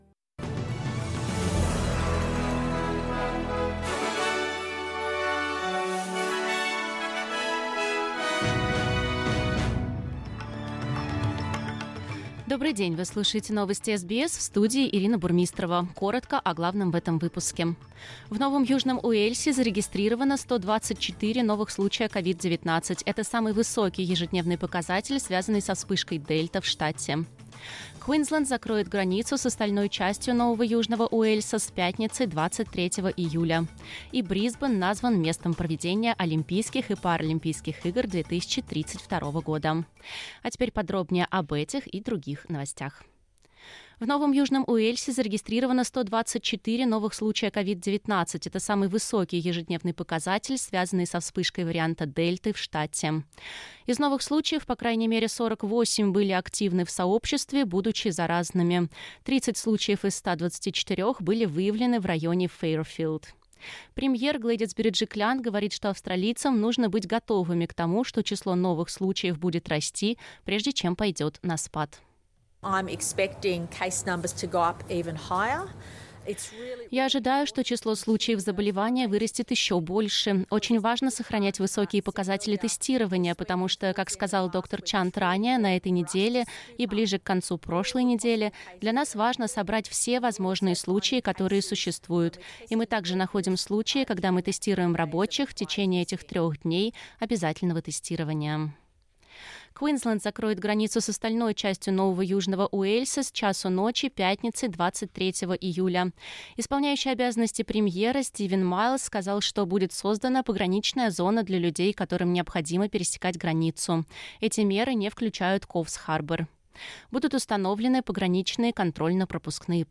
SBS news in Russian - 22.07